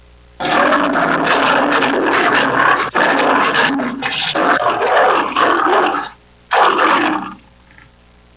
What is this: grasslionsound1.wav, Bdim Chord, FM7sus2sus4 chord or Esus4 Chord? grasslionsound1.wav